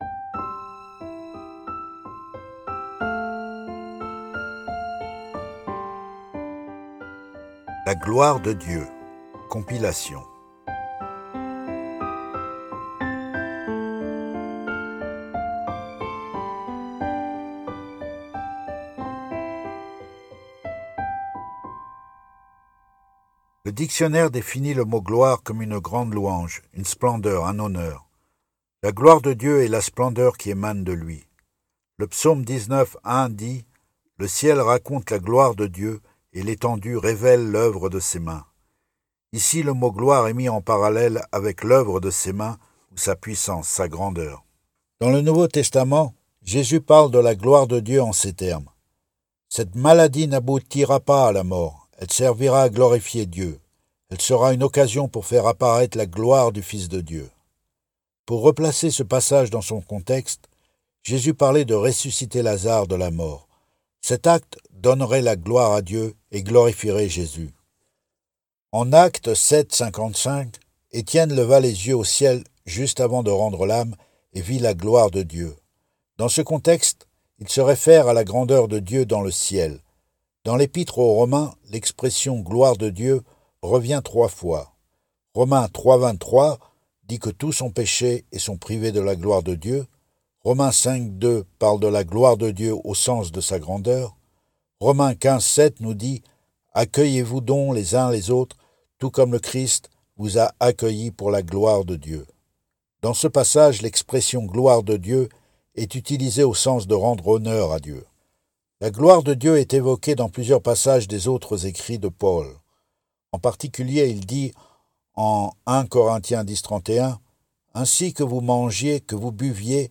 Compilation